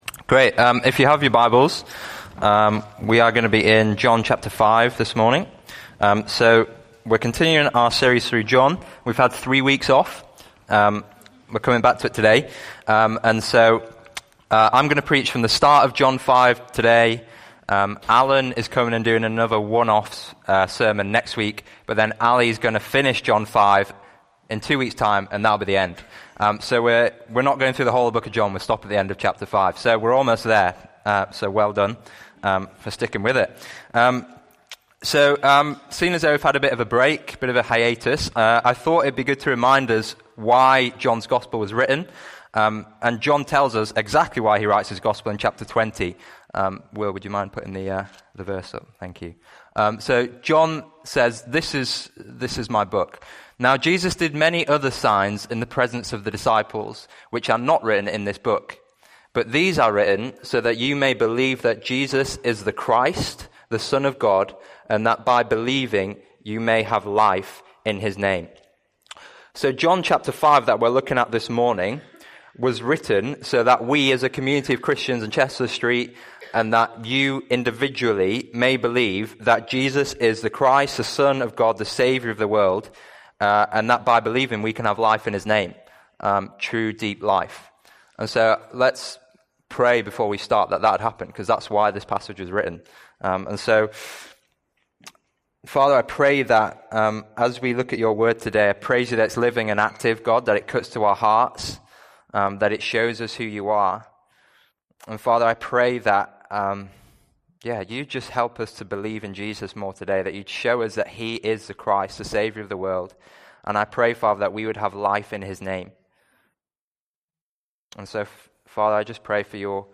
A sermon series from Emmanuel Church during early 2019.